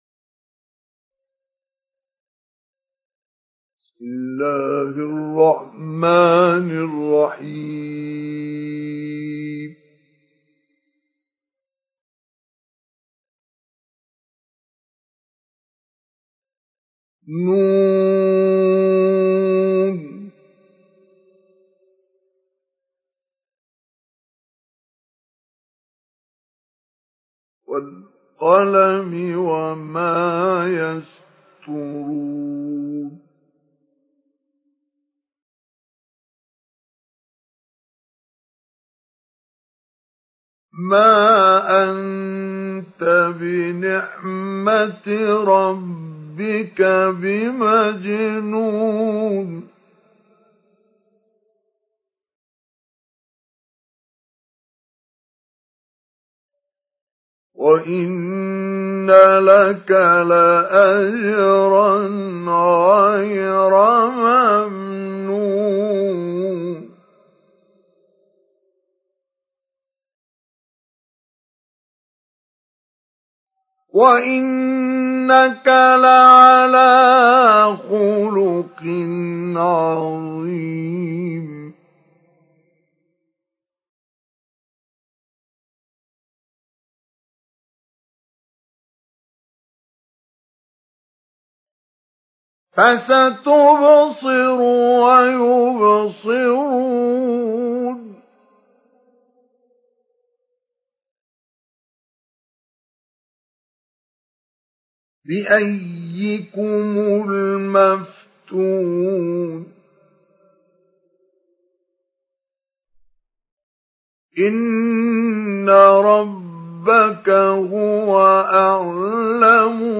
سُورَةُ القَلَمِ بصوت الشيخ مصطفى اسماعيل